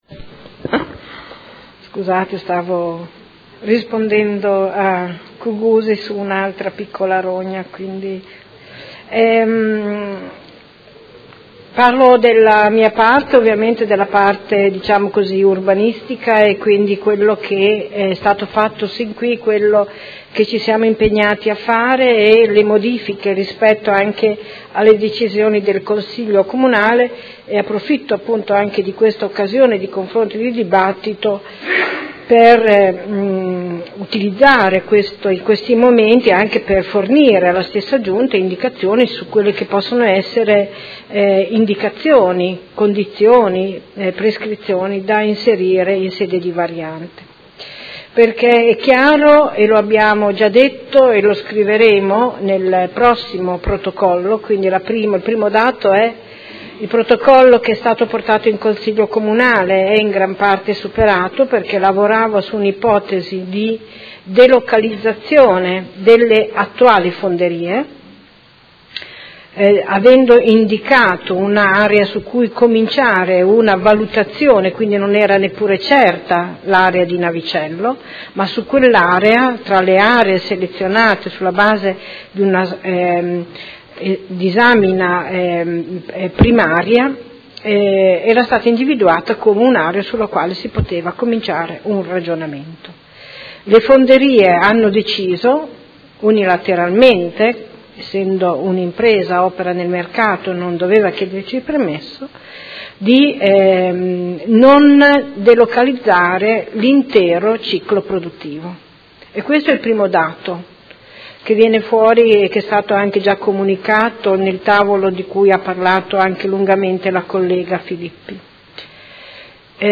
Seduta del 22/11/2018. Dibattito su interrogazioni sul tema delle Fonderie Cooperative di Modena